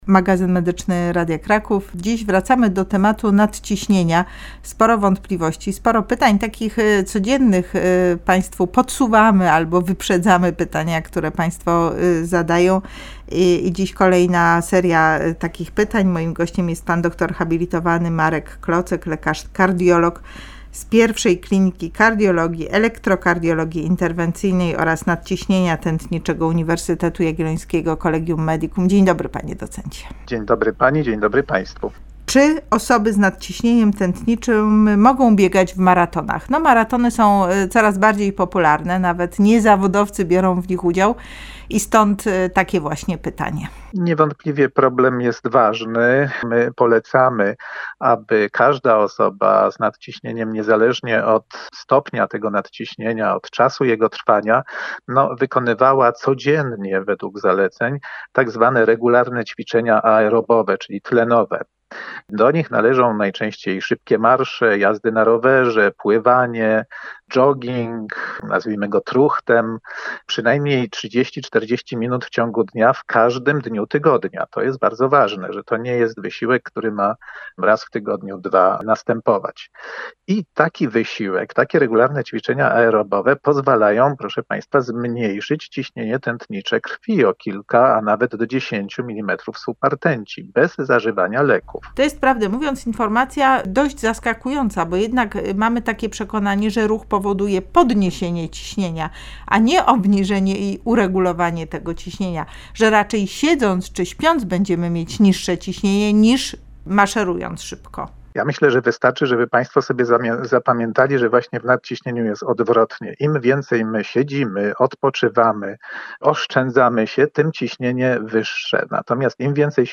Czy osoby z nadciśnieniem mogą chodzić w wysokie góry, latać samolotem i biegać w maratonach? Kiedy powinny zachować szczególną ostrożność? Rozmawiają lekarz kardiolog